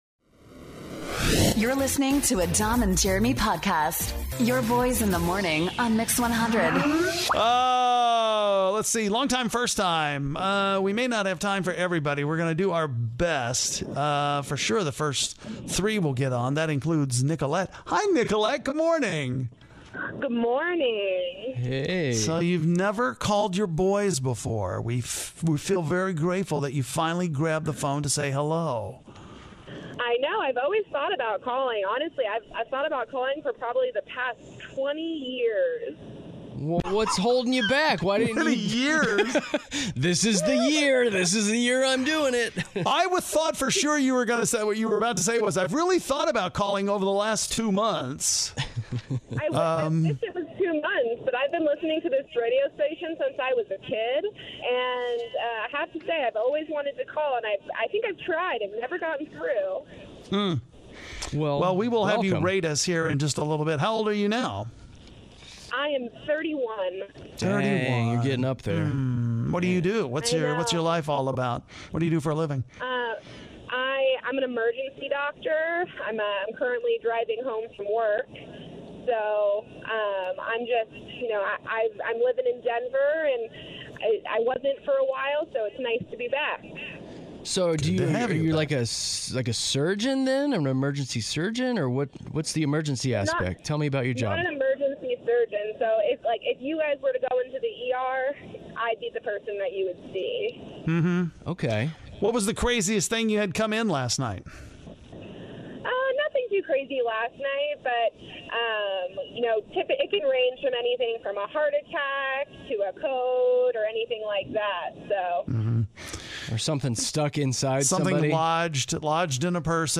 We talk to listeners who have never called in before!